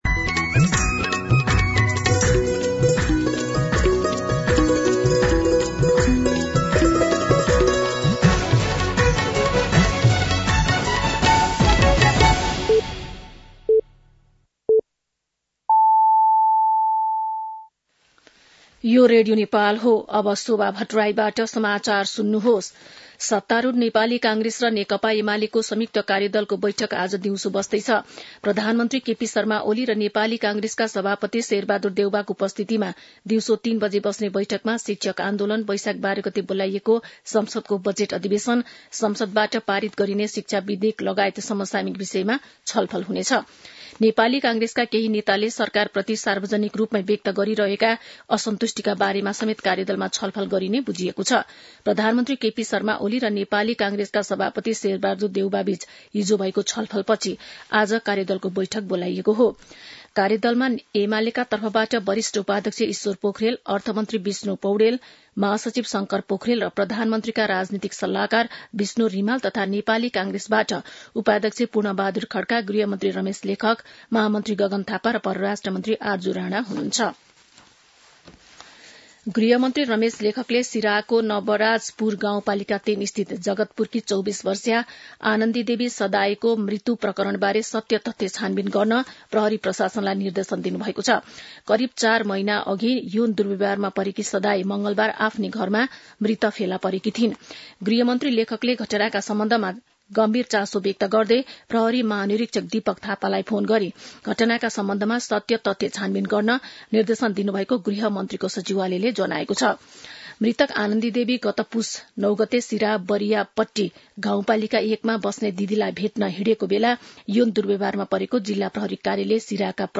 दिउँसो १ बजेको नेपाली समाचार : ३ वैशाख , २०८२
1-pm-news-1-6.mp3